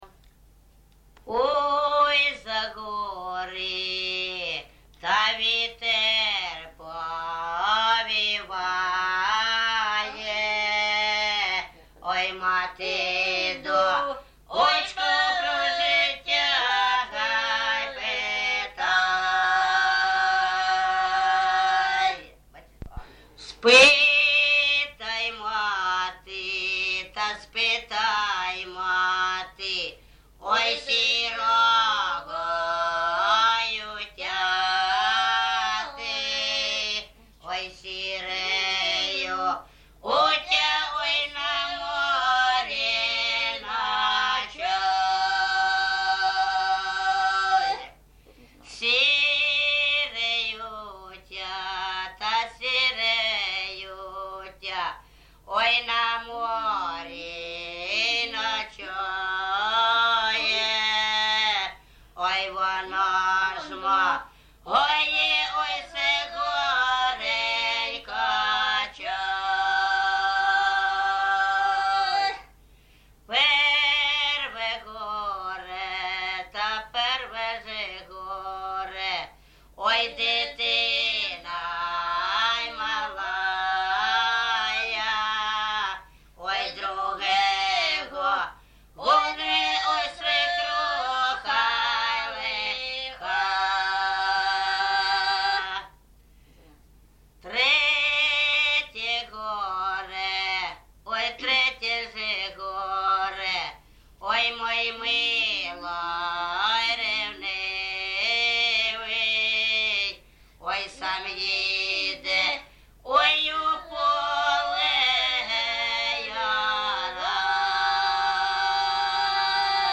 ЖанрПісні з особистого та родинного життя
Місце записус. Гарбузівка, Сумський район, Сумська обл., Україна, Слобожанщина